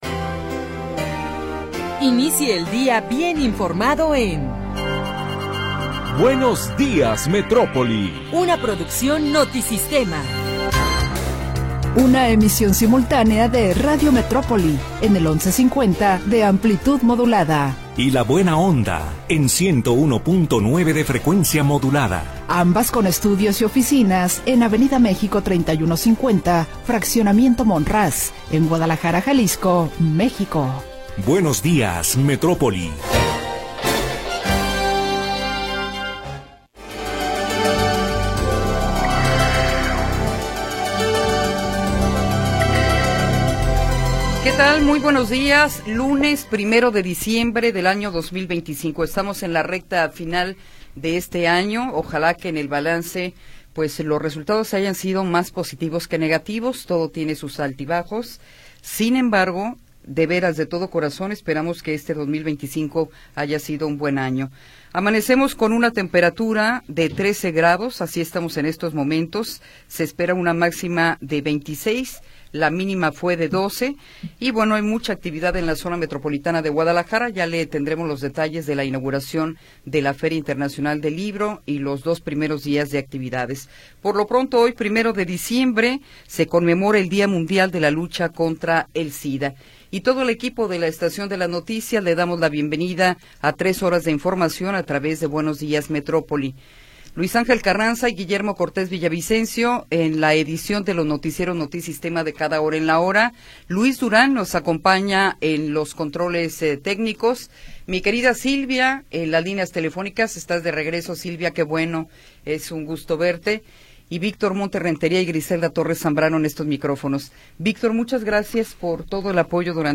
Primera hora del programa transmitido el 1 de Diciembre de 2025.